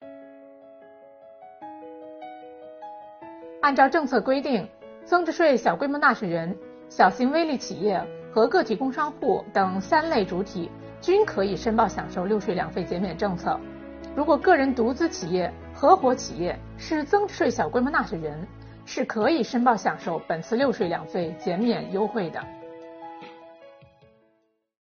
本期课程由国家税务总局财产和行为税司副司长刘宜担任主讲人，解读小微企业“六税两费”减免政策。今天，我们一起学习：哪些纳税人可以享受小微企业“六税两费”减免政策？